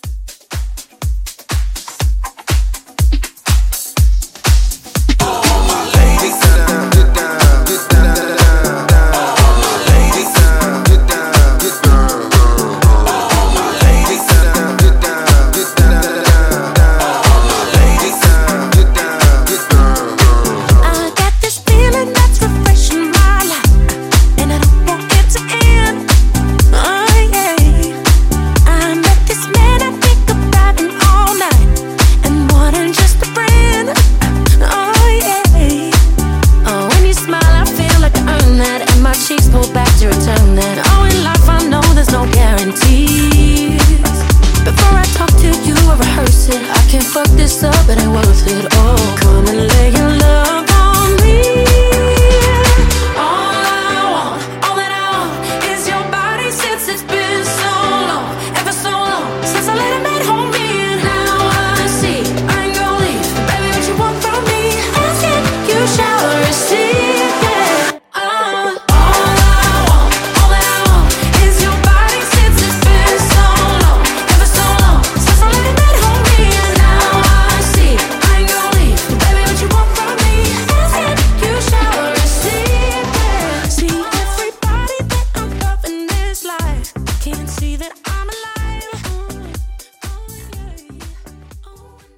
Genres: DANCE , RE-DRUM , TOP40
Dirty BPM: 122 Time